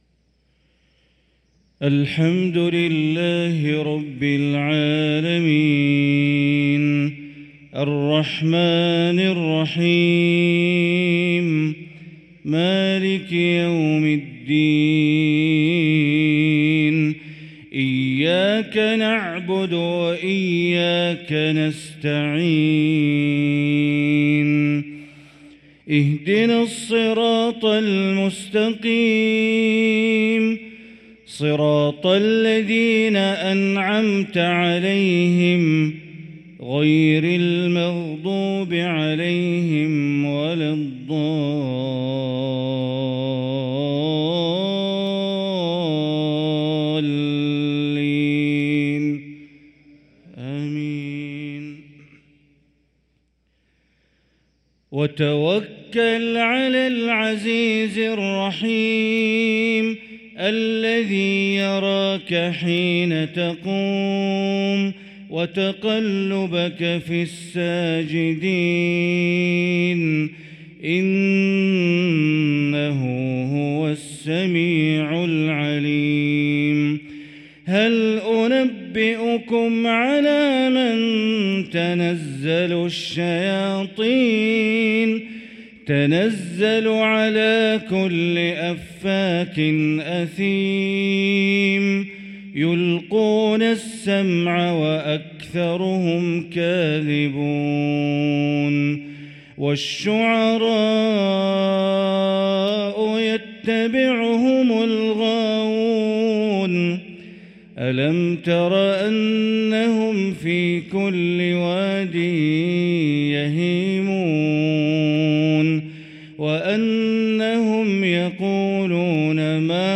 صلاة المغرب للقارئ بندر بليلة 29 ربيع الآخر 1445 هـ